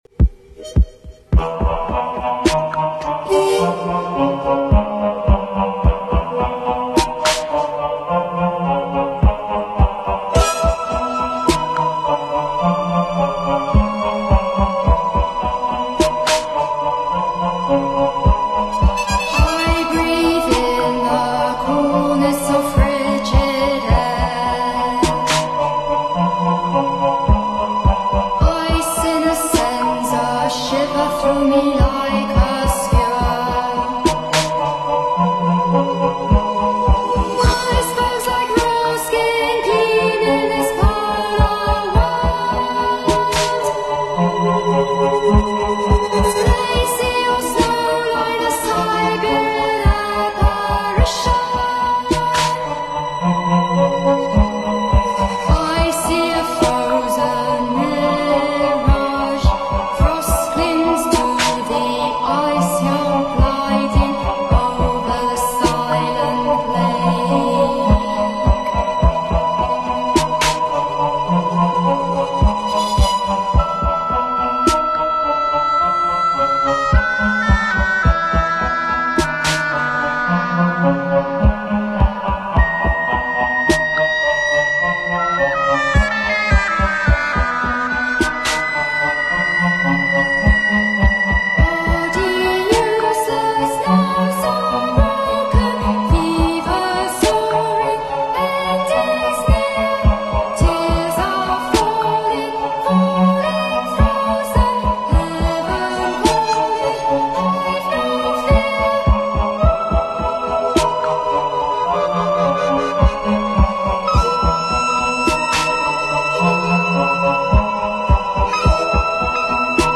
ethereal, pastoral and elusive